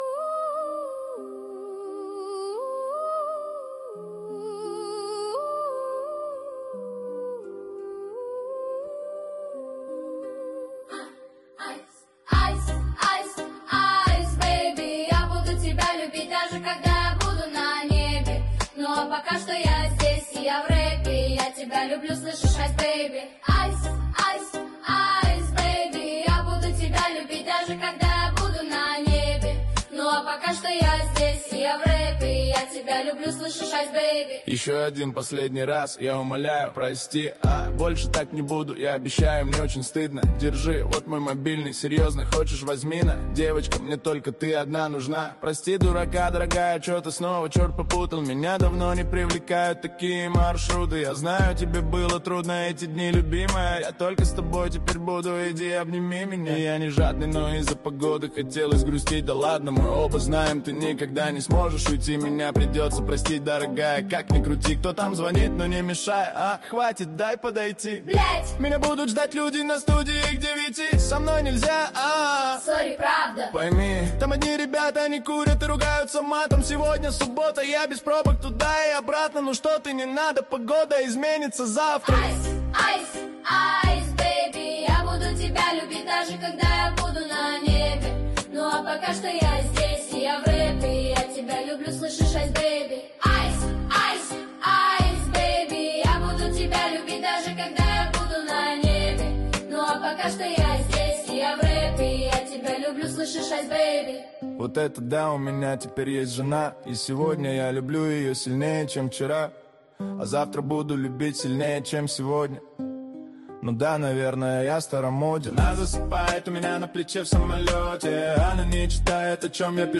Ремиксы